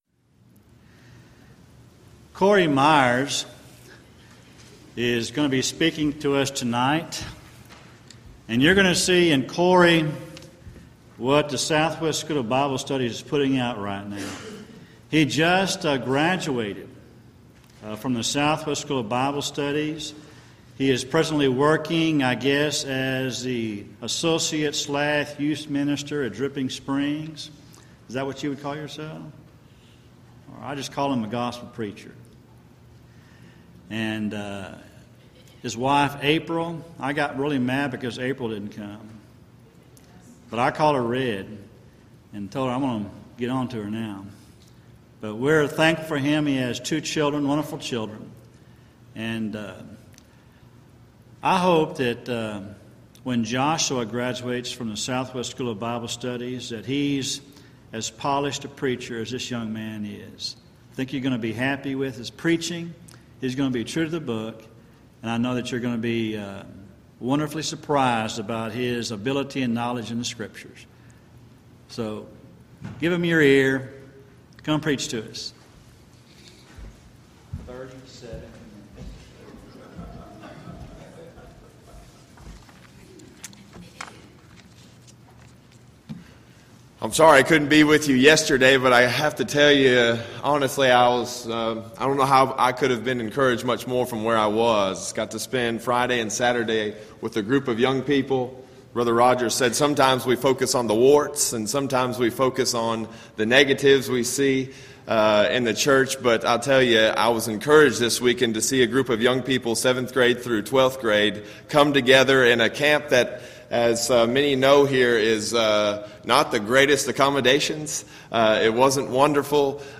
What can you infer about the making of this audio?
Event: 3rd Annual Back to the Bible Lectures